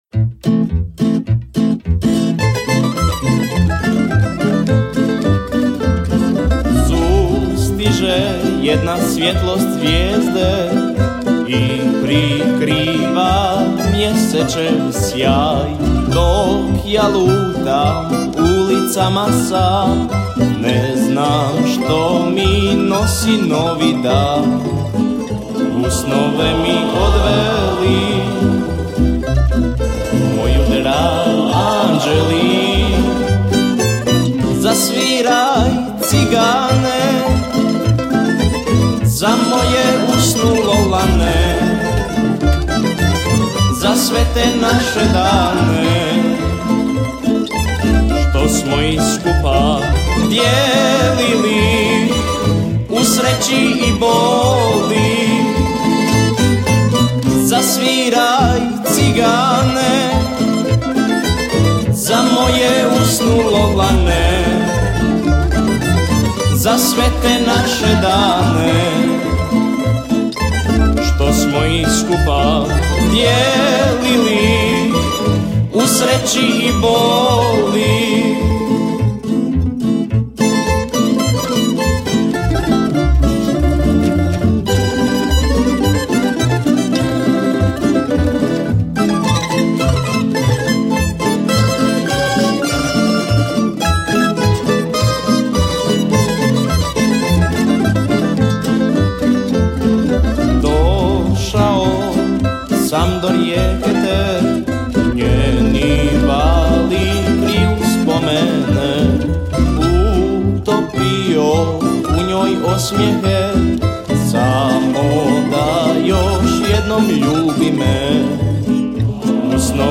Na festivalu je ove godine nastupilo 19 izvođača s novim autorskim pjesmama, od toga je bilo 8 tamburaških sastava te 11 solista.